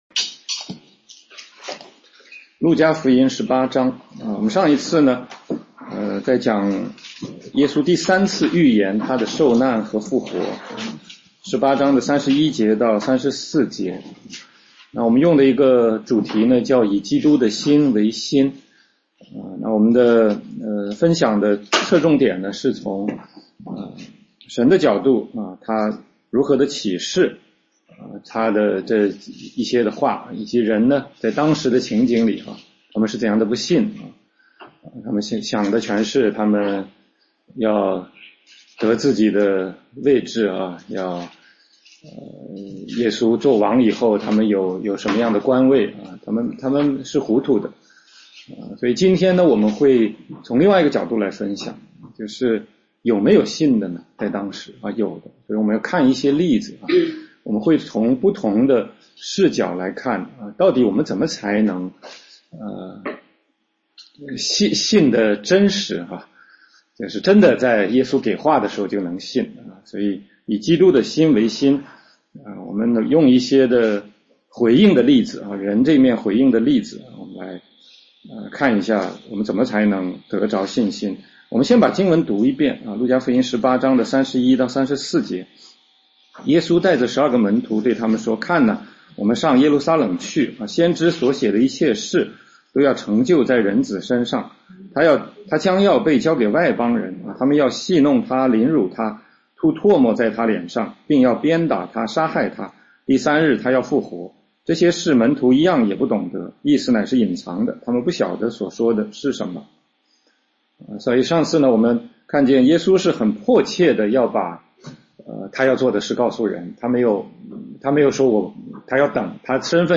16街讲道录音 - 路加福音18章31-34节：以基督的心为心（2）
全中文查经